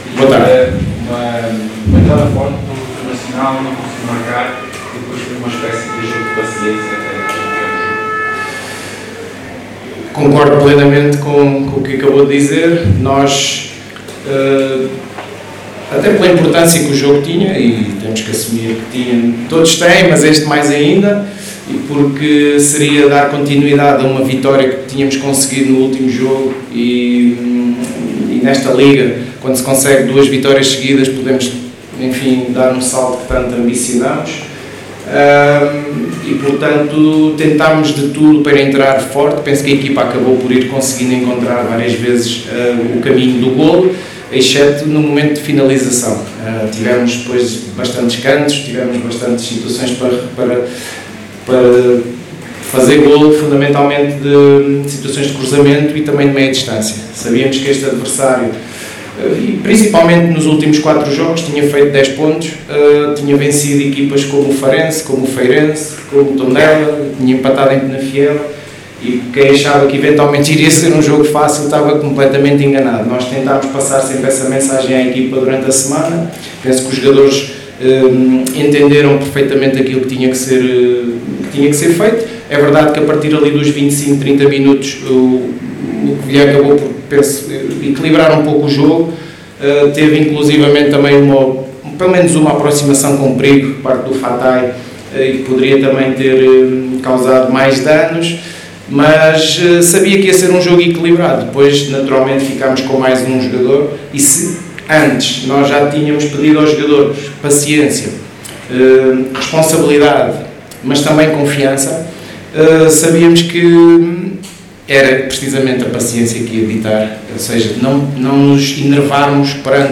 Na conferência de imprensa realizada no final do jogo com o Covilhã